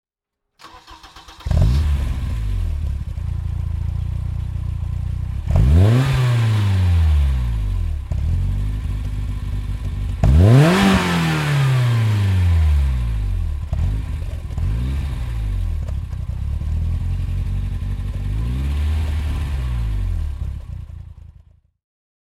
BMW M3 E30 "Cecotto" (1989) - Starten und Leerlauf